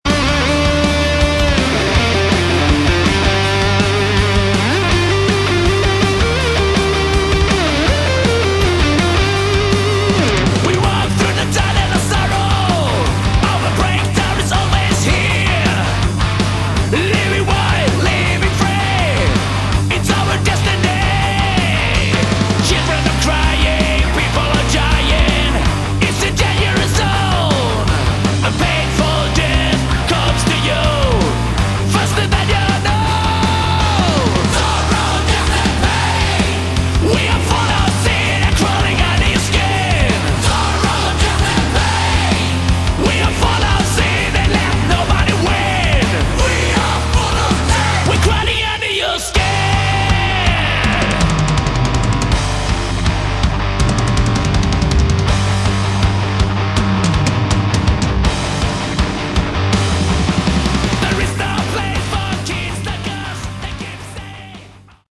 Excellent sleazy hard rock!
This is good old-school dirty sleazy hard rock.